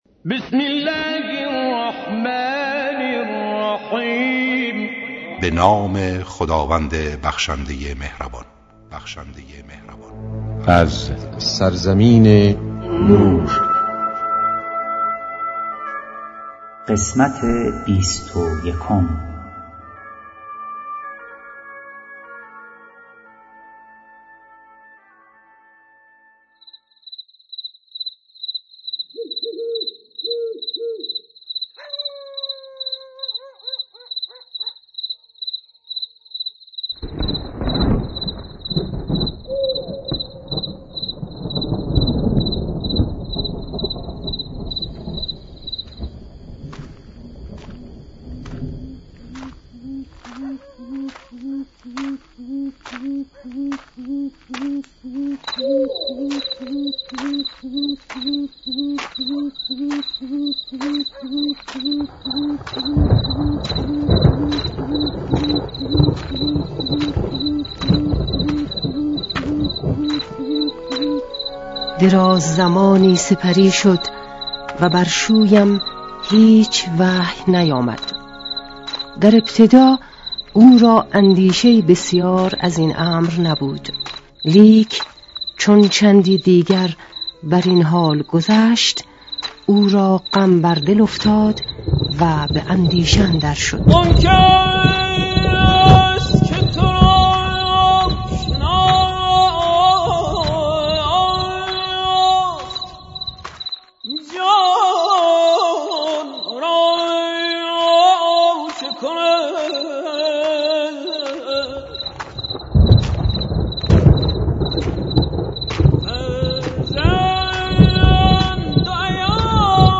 با اجرای مشهورترین صداپیشگان، با اصلاح و صداگذاری جدید
کتاب گویا